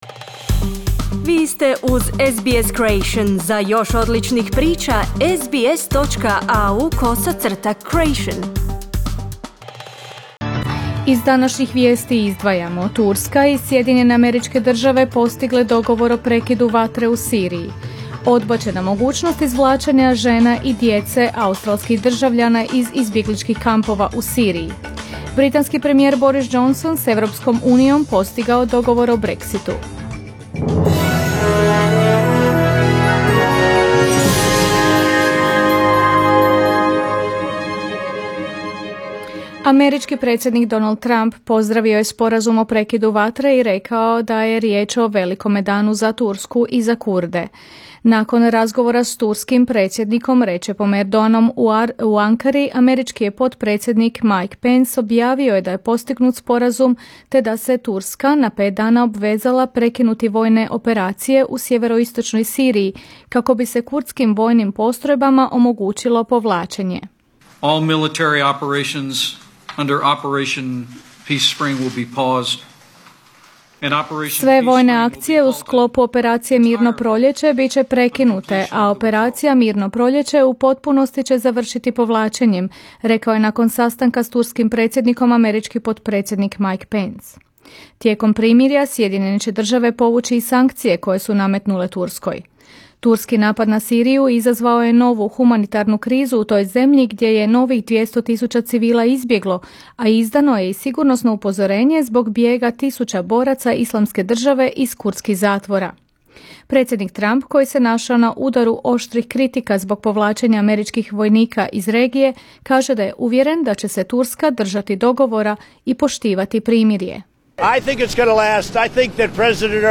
Vijesti radija SBS